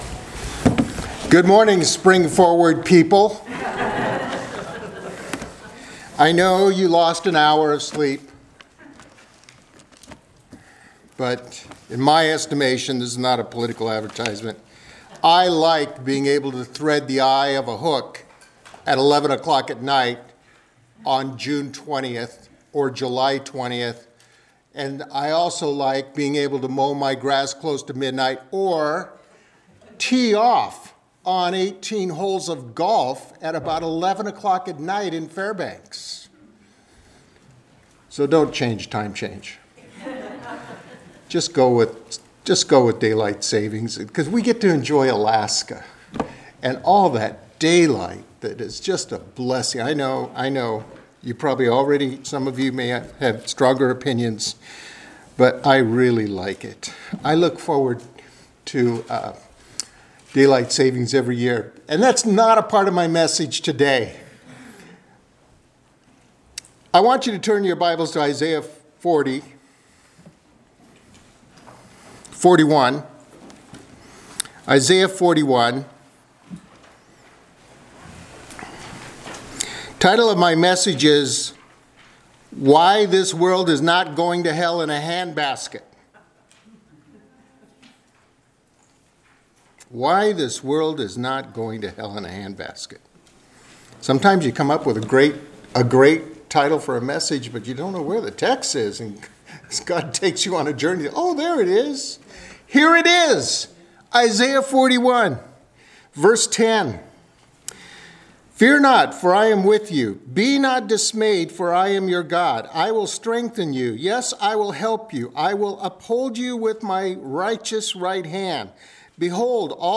Passage: Isaiah 41:10-16 Service Type: Sunday Morning Worship Topics